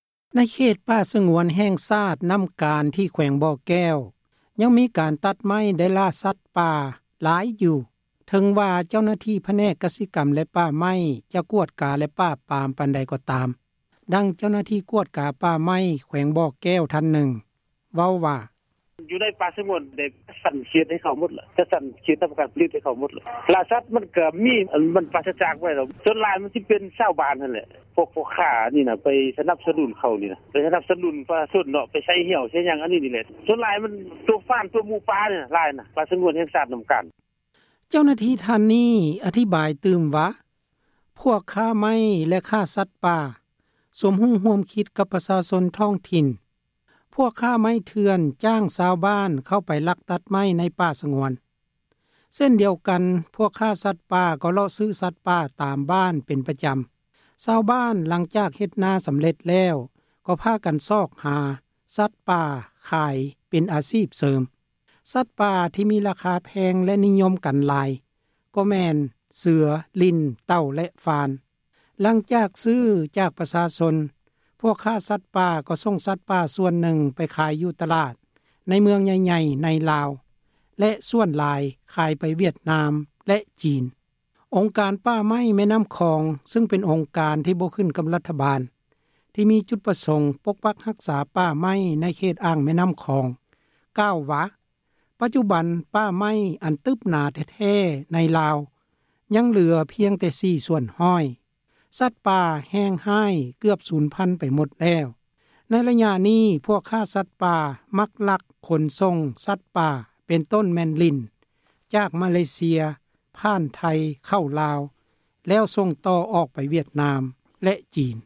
ດັ່ງ ເຈົ້າໜ້າທີ່ ປ່າໄມ້ ແຂວງ ບໍ່ແກ້ວ ເວົ້າວ່າ: